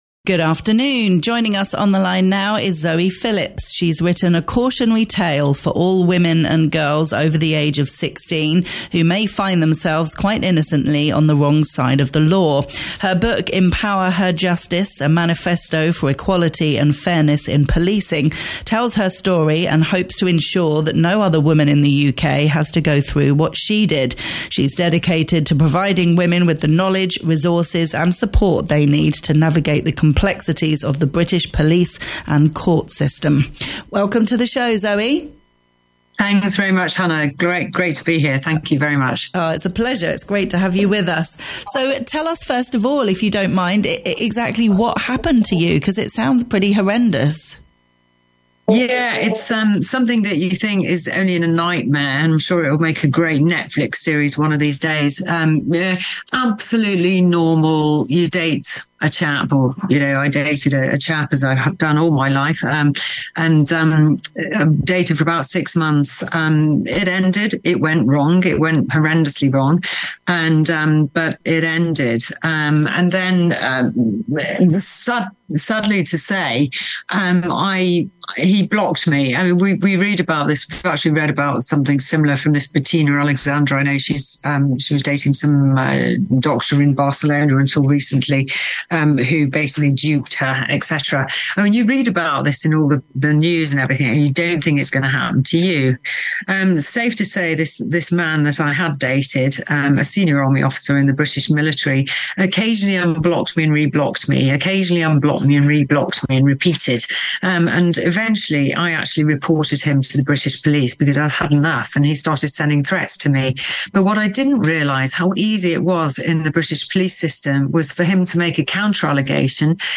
Talk Radio Europe Interview live - click here